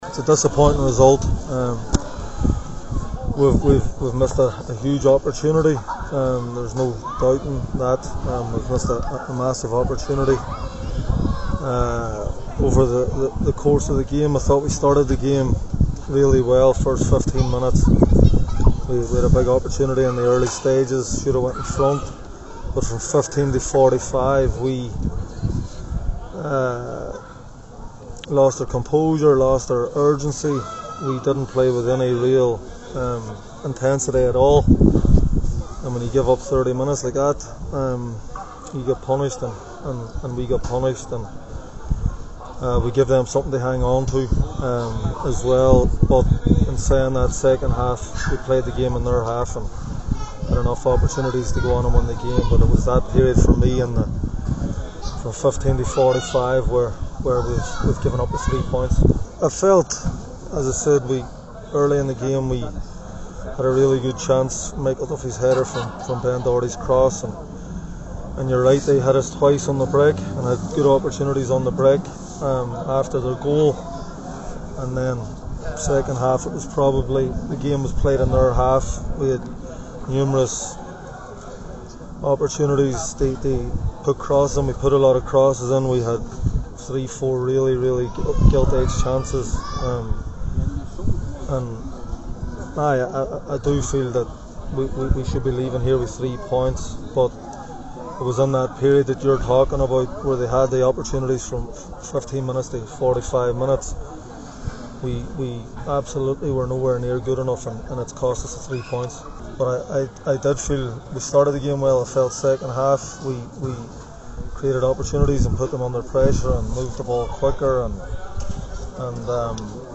spoke to the assembled press…